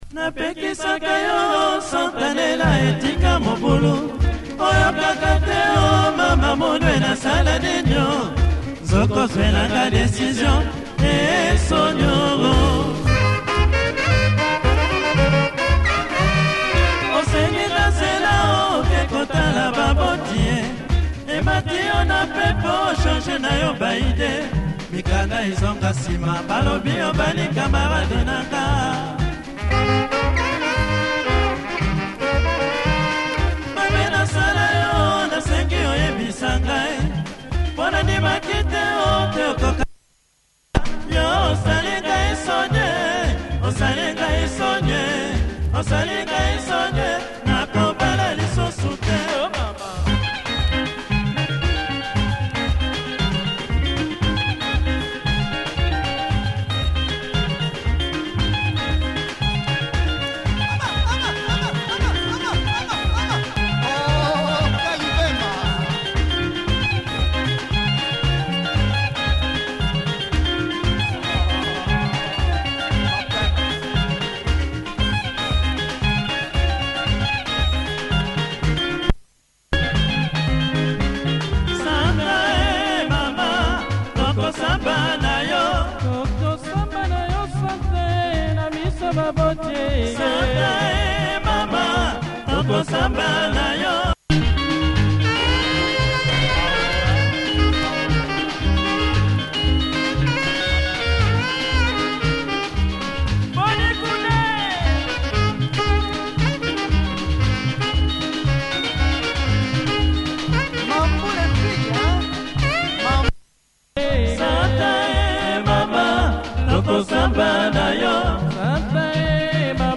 Classic Lingala
best horn section around!